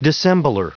Prononciation du mot dissembler en anglais (fichier audio)
Prononciation du mot : dissembler